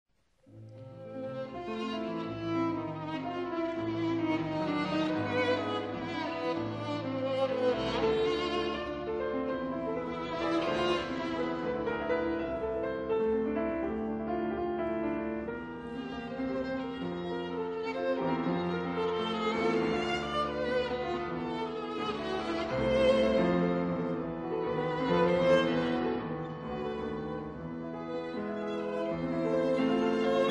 第1楽章　アレグロ・アパッショナート“情熱的な表情を持って”
第2楽章　アレグレット
第3楽章　アレグロ・ユン・ブリオ“生きいきと”
しかし、まだこの1番の方が、簡潔でひきやすく、1楽章の抒情的な第1主題、詩情味あふれる2楽章、作曲者の情熱的な感情がこぼれ出てきそうな3楽章と、ロマン的でかつ力強い作品となっている。
Piano